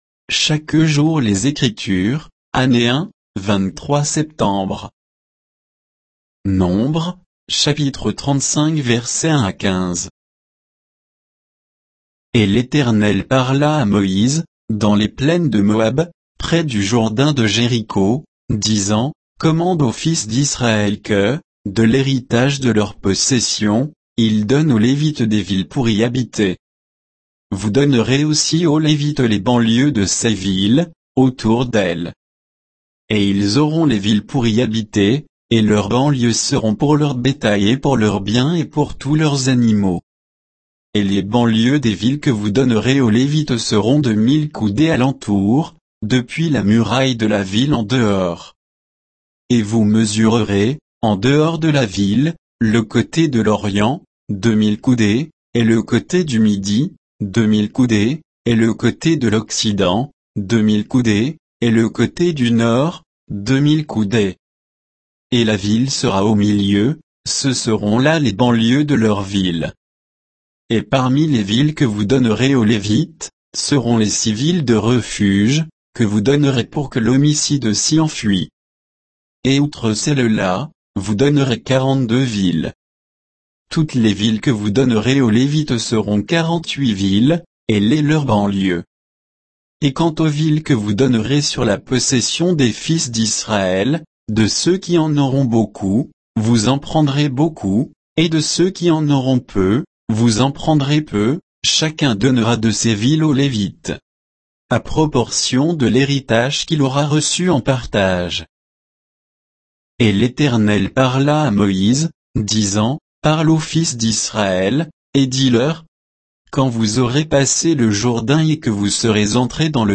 Méditation quoditienne de Chaque jour les Écritures sur Nombres 35